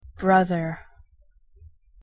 LAwc3MjnA4o_pronunciation-en-brother.mp3